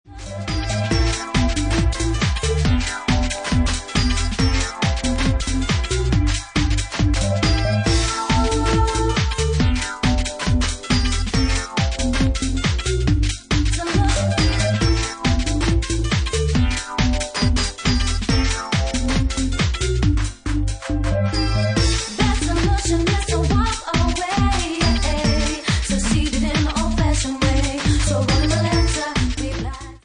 Bassline House at 69 bpm